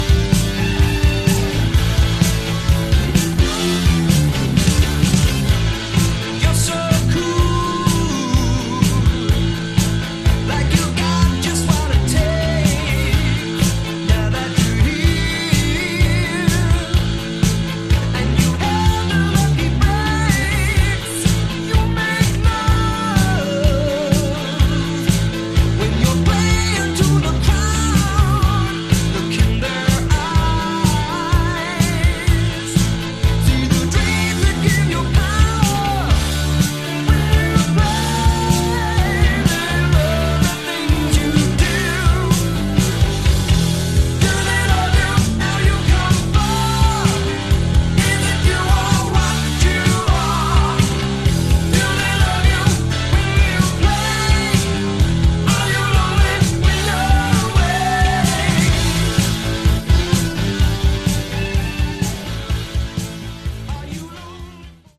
Category: Hard Rock
vocals
guitar
bass
Keys
drums